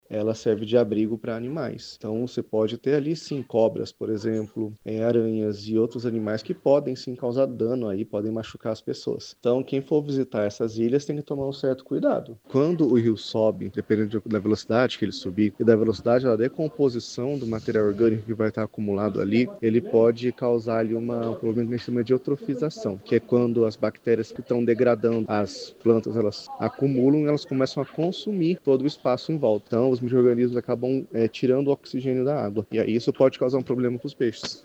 SONORA02_ILHAS-DE-CANARANAS.mp3